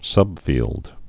(sŭbfēld)